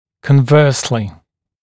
[kən’vɜːslɪ][кэн’вё:сли]обратно, наоборот